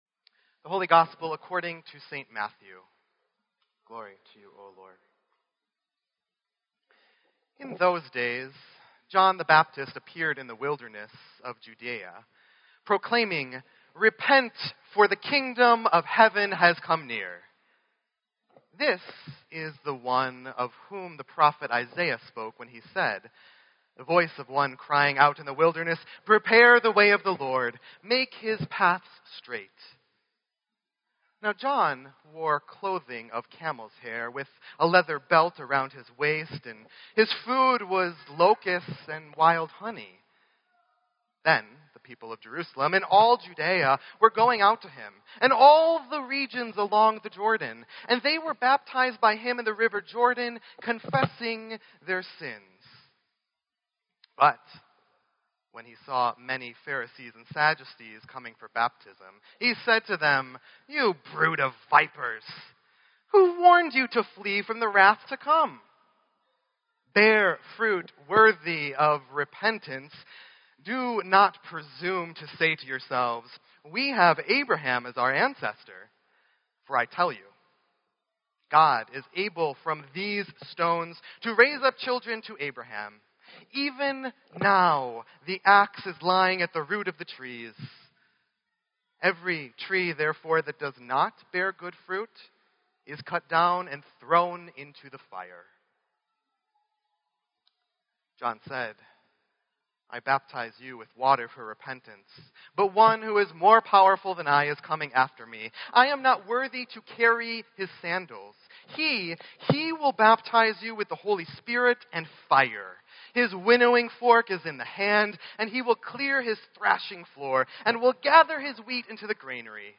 Sermon_12_4_16.mp3